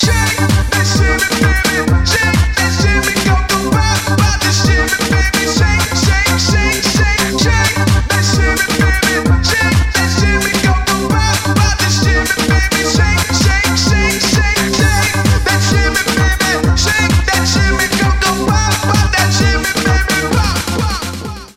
евродэнс